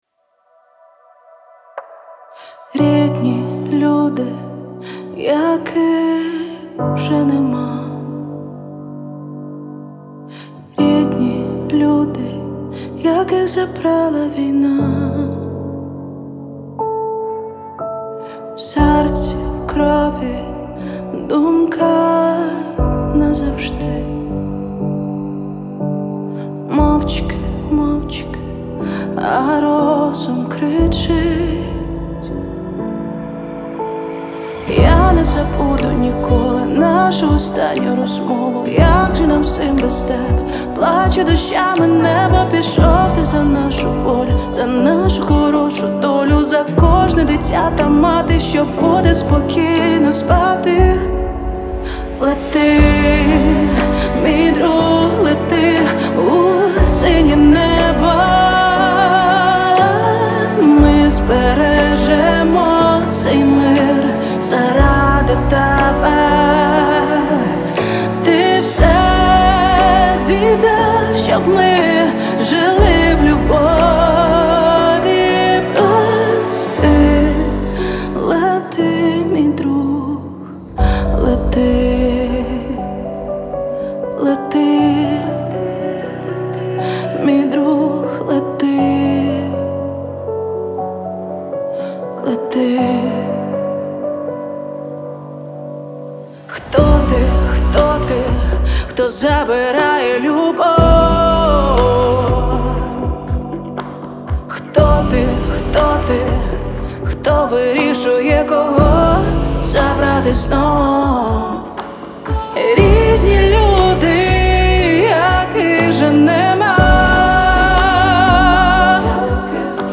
• Жанр: Pop